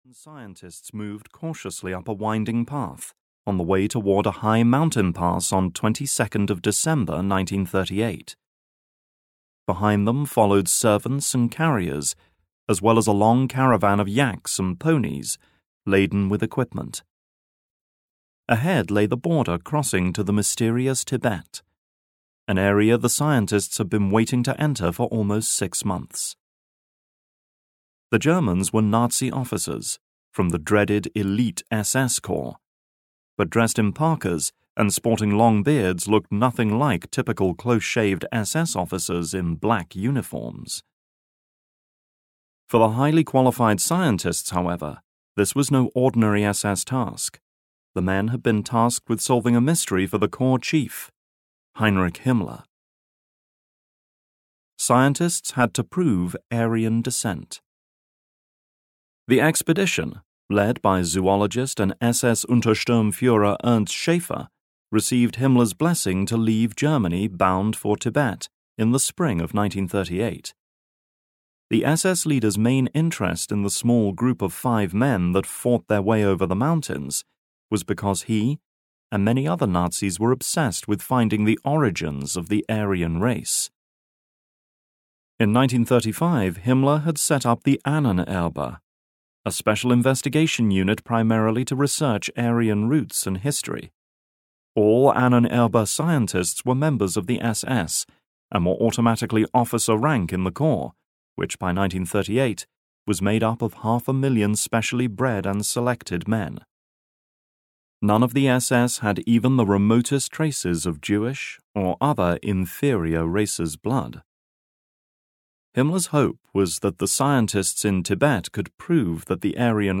Unsolved Mysteries of WWII (EN) audiokniha
Ukázka z knihy